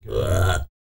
TUVANGROAN15.wav